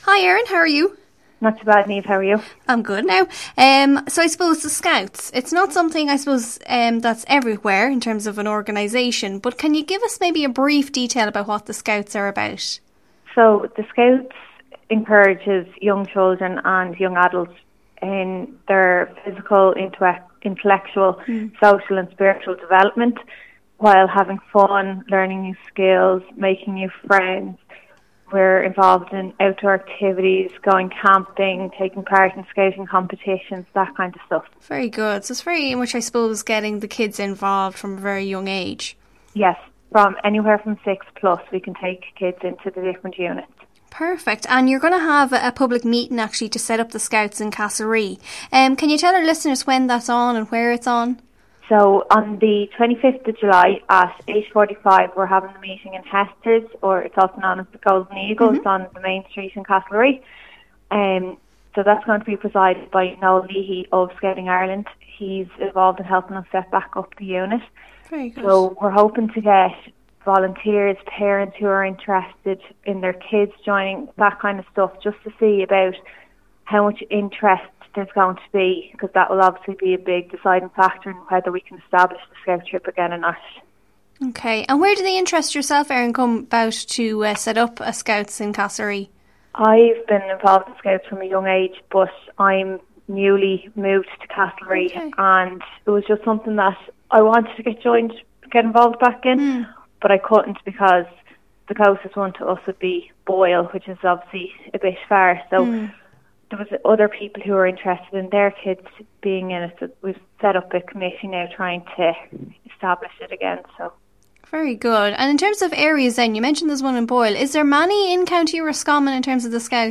Castlerea Scouts Interview - RosFM 94.6